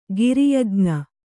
♪ giri yajña